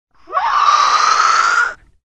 Звук гибнущей птицы или чудовища